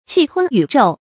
氣吞宇宙 注音： ㄑㄧˋ ㄊㄨㄣ ㄧㄩˇ ㄓㄡˋ 讀音讀法： 意思解釋： 猶氣吞山河。